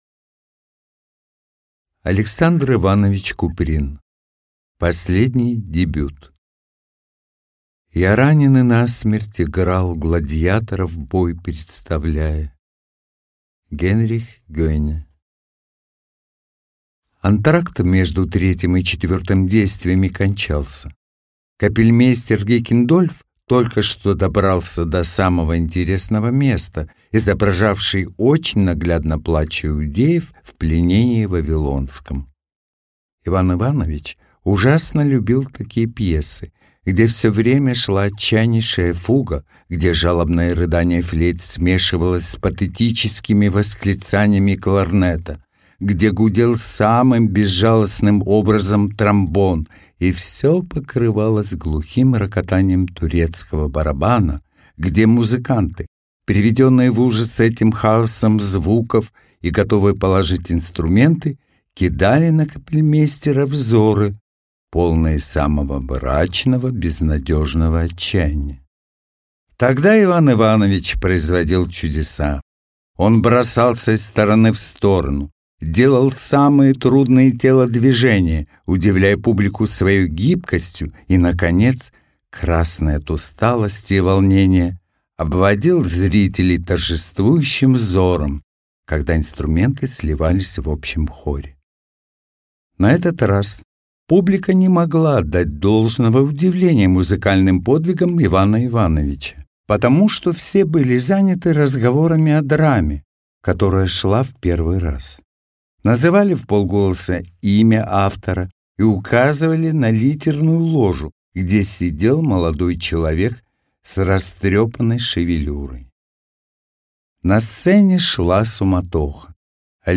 Аудиокнига Последний дебют | Библиотека аудиокниг
Aудиокнига Последний дебют